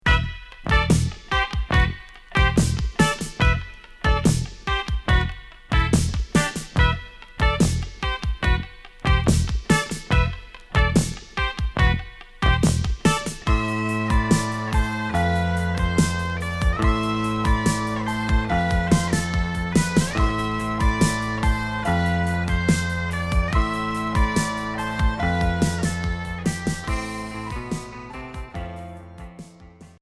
Cold wave Unique 45t retour à l'accueil